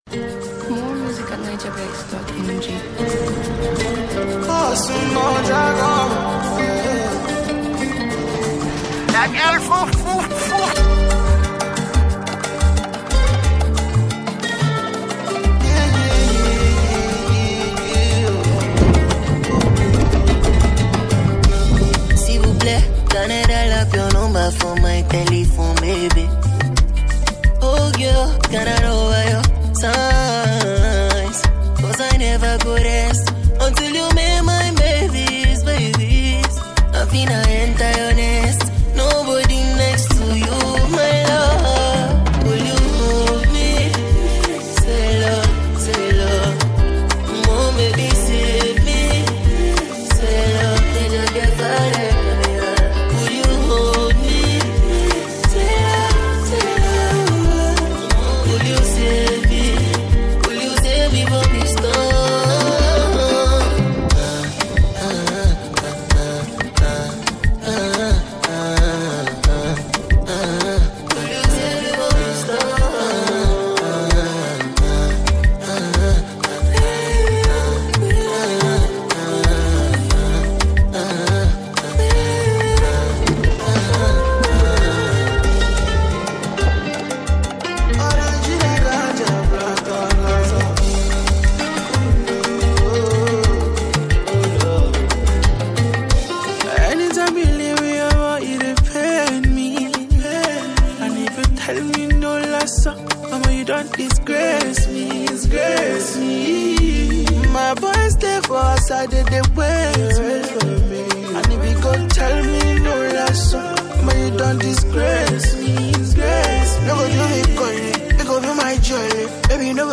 Afrobeats / Afro-fusion